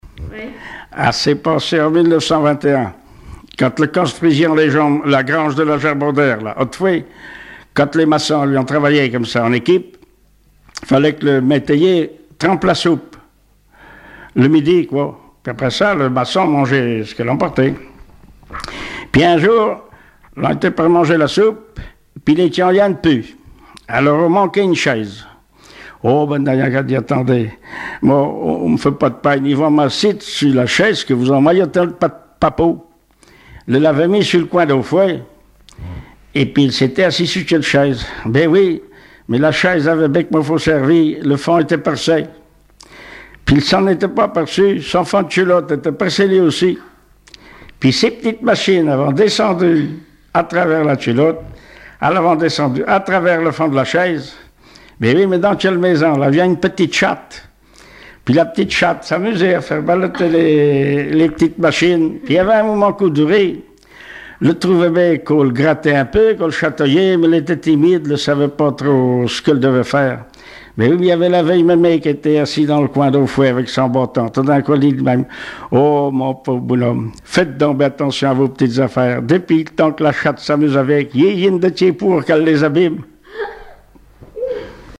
Genre sketch
Témoignages et chansons traditionnelles et populaires
Catégorie Récit